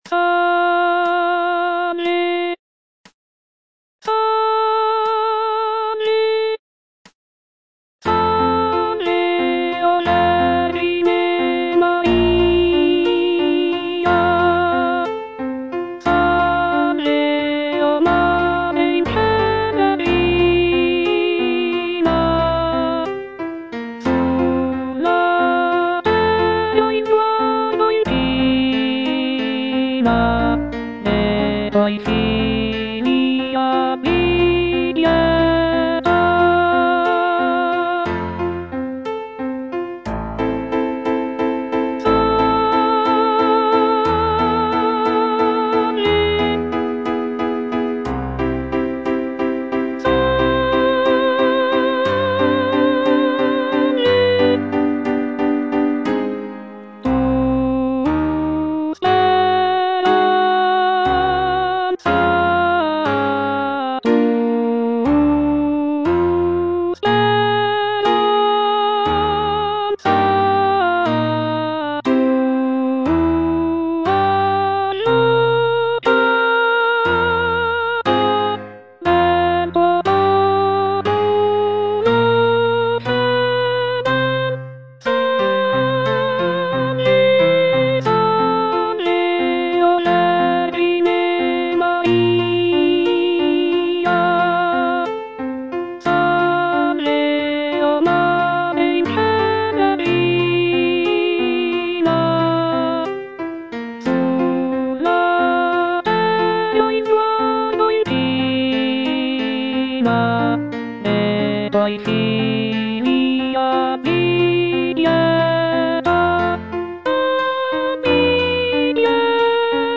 G. ROSSINI - SALVE O VERGINE MARIA (EDITION 2) Alto (Voice with metronome) Ads stop: auto-stop